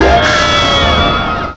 pokeemerald / sound / direct_sound_samples / cries / giratina.aif